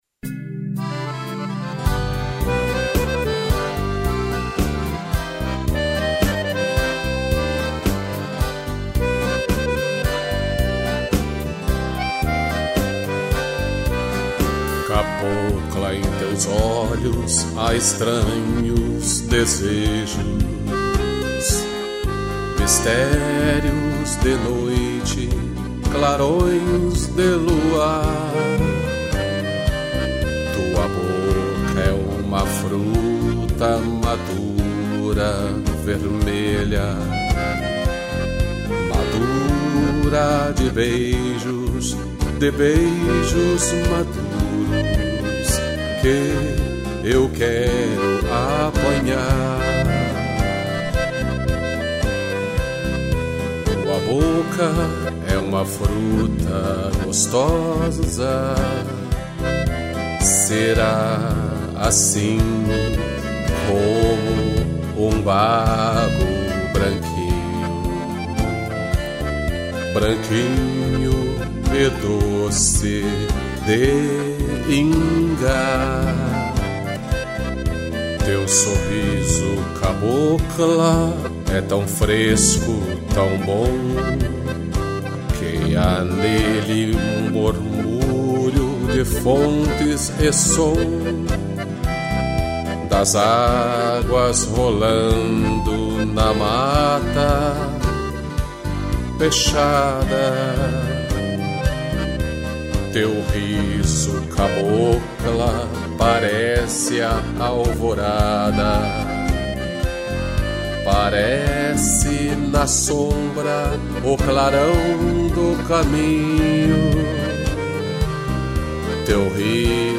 Voz
acordeão